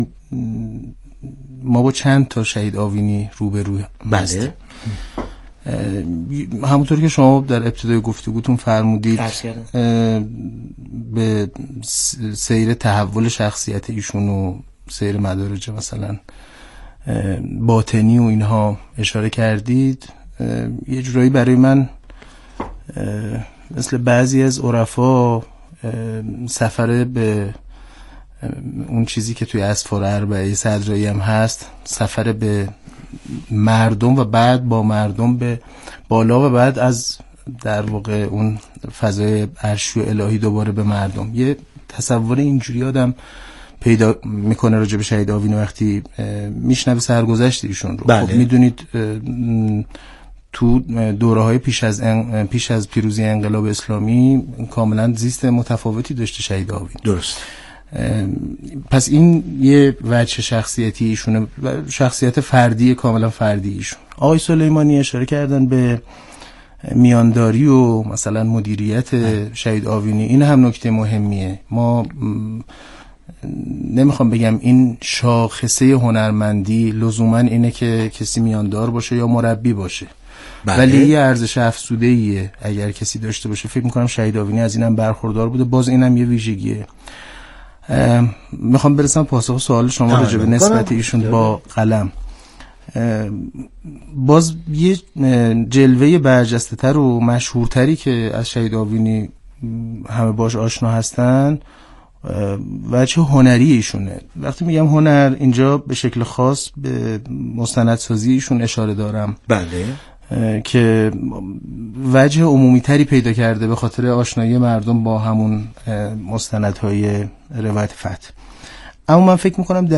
میزگرد تعاملی ایکنا